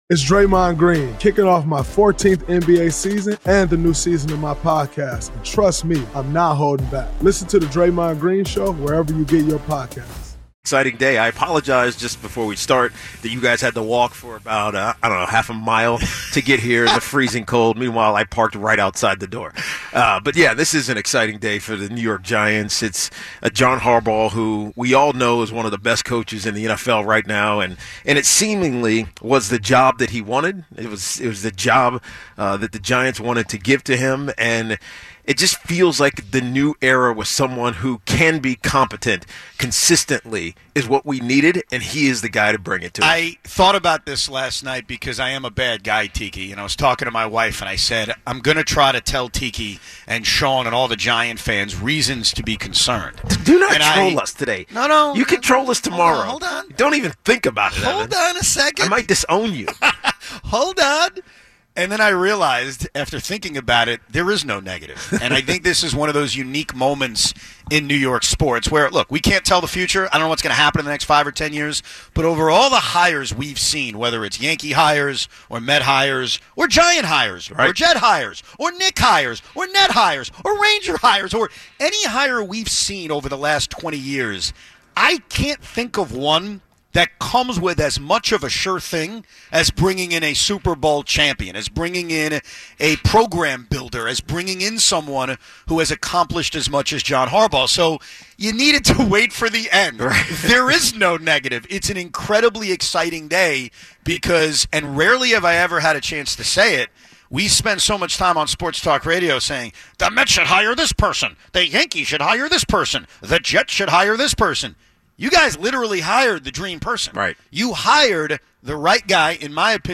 Live from the Quest Diagnostics Training Center ahead of Harbaugh’s introductory press conference, they debate how fast he can flip the culture, what realistic expectations should be for next season, and whether Giants fans should already be thinking playoffs.
The guys lay out the three options, ride it out, dump KAT, or fire the coach, and explain why none of them feel clean. Plus, fan calls on what Harbaugh means for the Giants and what’s actually wrong with the Knicks right now.